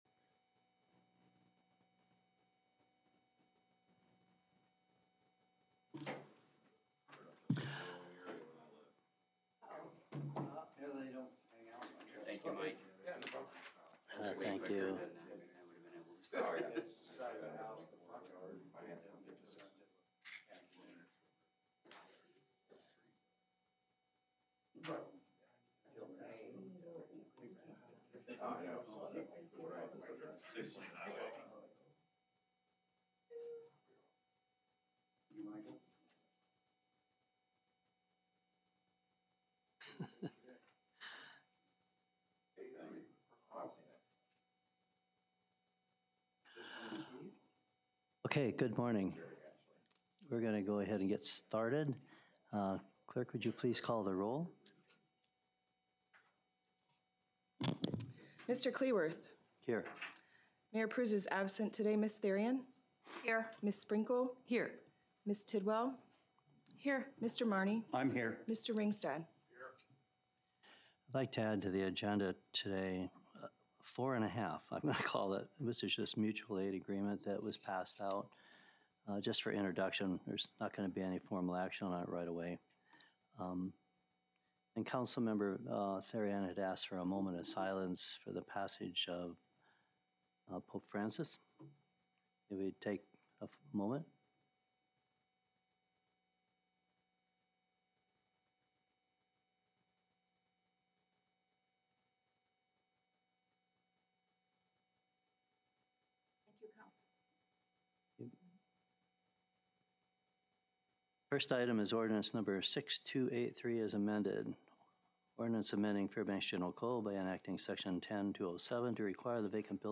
Regular City Council Work Session
Location Patrick B Cole City Hall 800 Cushman Street Fairbanks, AR 99701